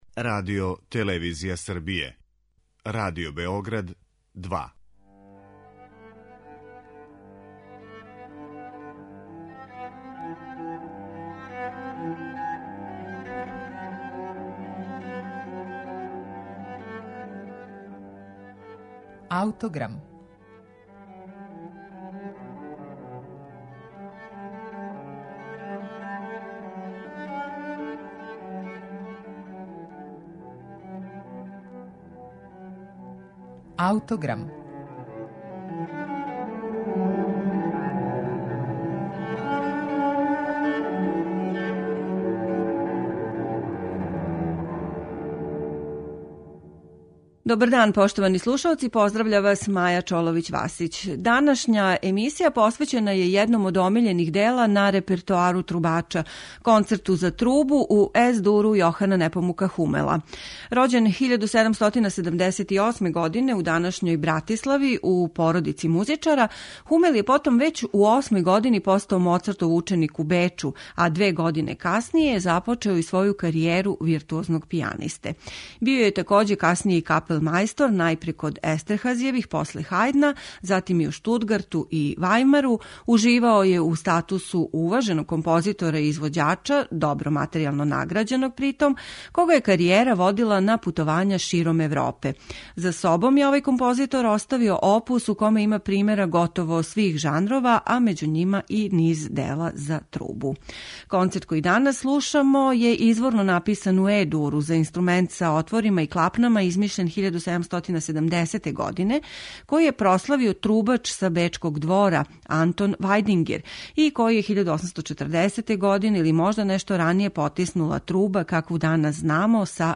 Концерт уобичајене троставачне структуре и препознатљивог класичарског израза представља омиљени део реперотара бројних трубача. Данас ћете га слушати у интерпетацији славног Мориса Андреа.